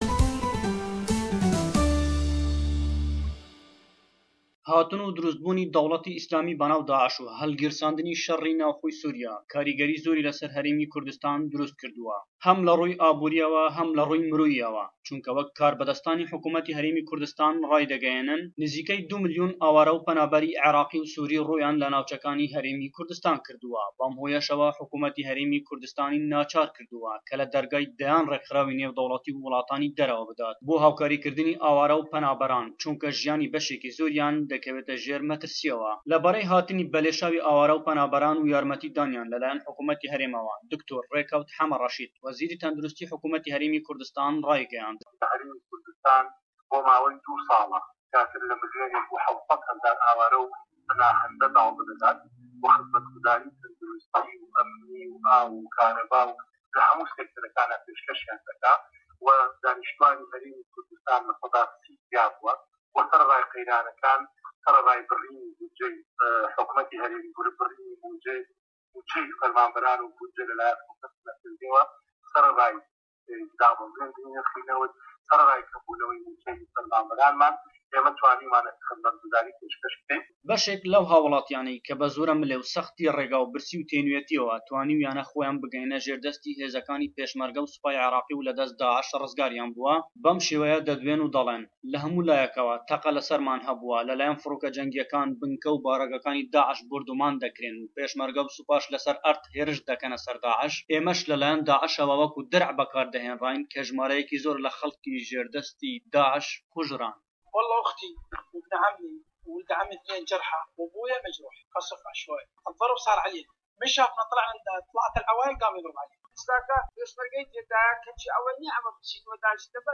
Raportêkî taybet